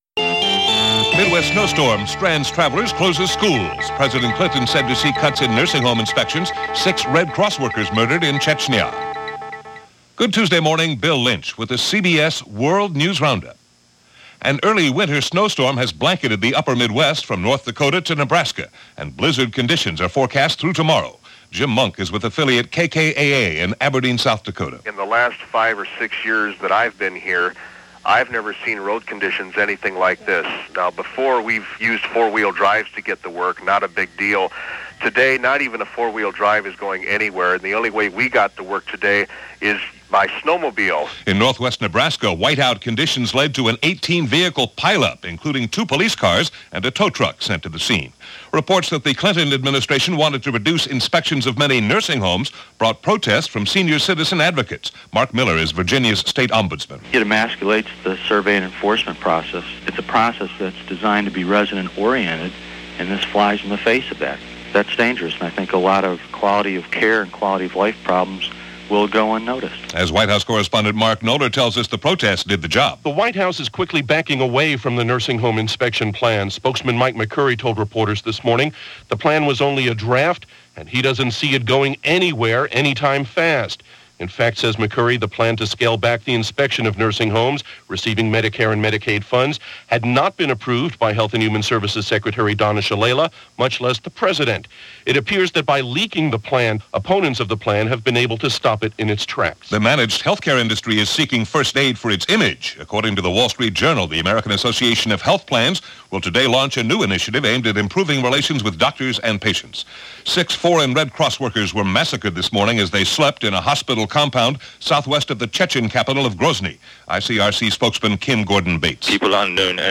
And that’s what was going on, December 17, 1996 as reported by The CBS World News Roundup.